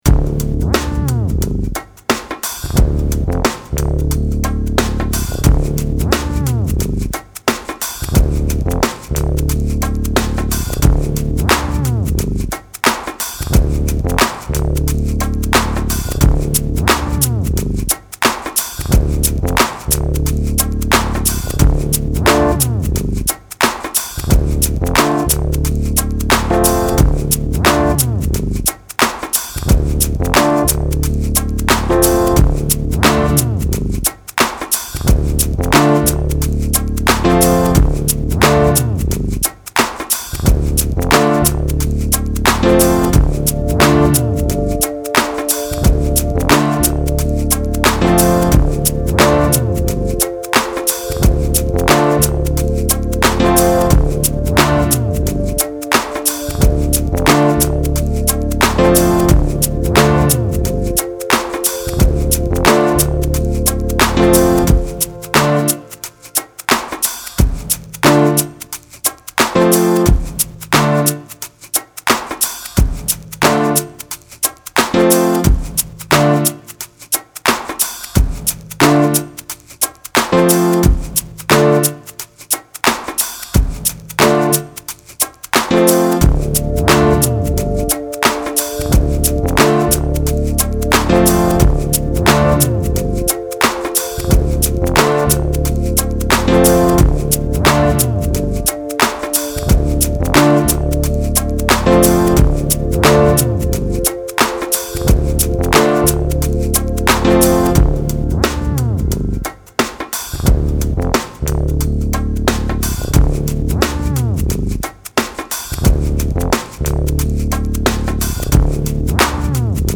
Gemafreie Musik aus Berlin-Kreuzberg